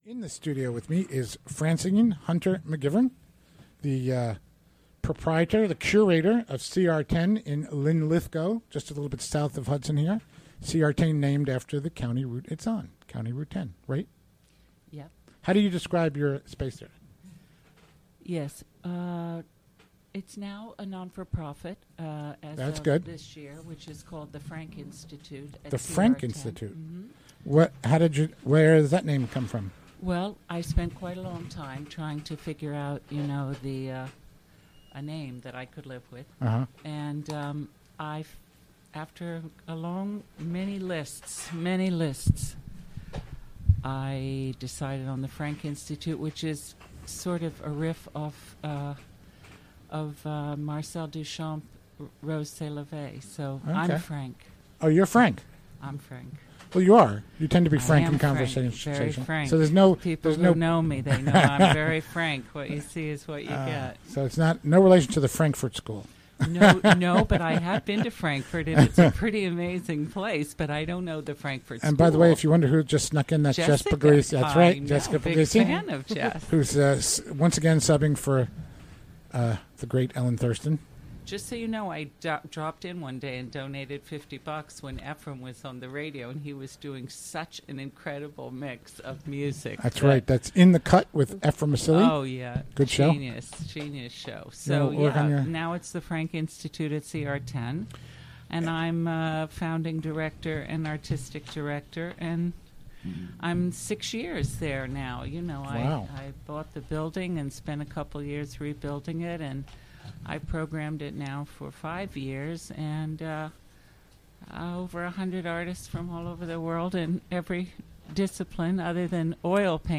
Interview recorded during the WGXC Afternoon Show, Thu., Aug. 18.